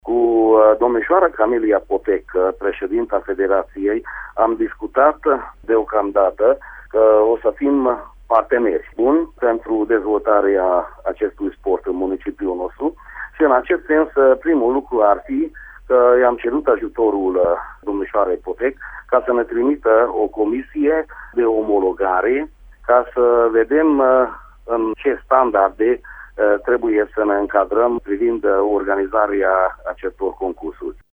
Despre această întâlnire vorbește viceprimarul din Miercurea Ciuc, Füleki Zoltán: